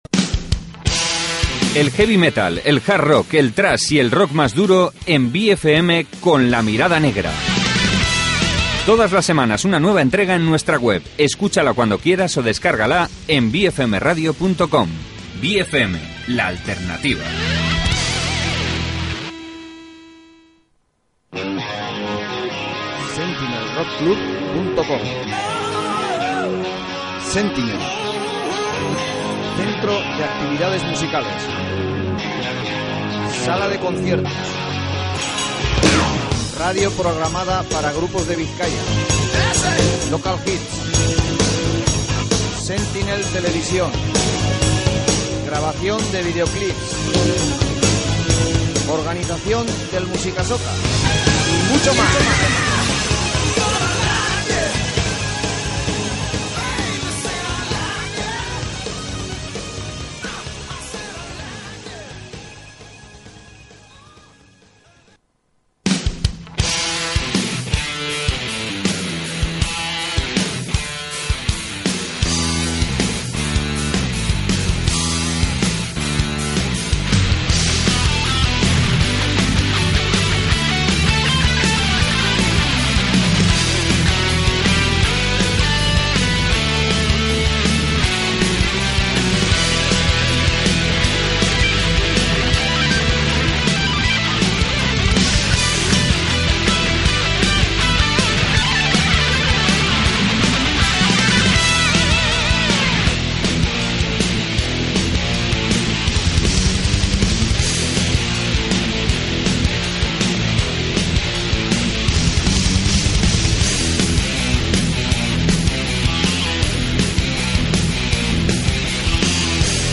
Entrevista con Calcetines Y Cachetes